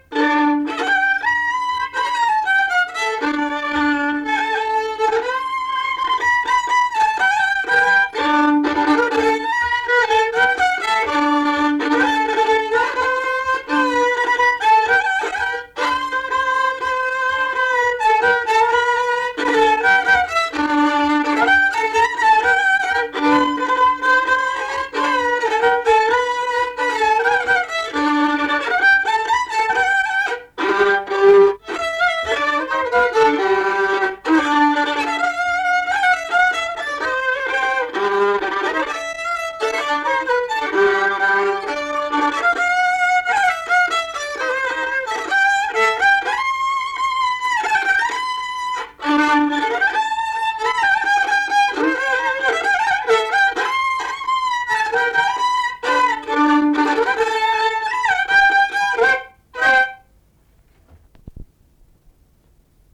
šokis
instrumentinis
smuikas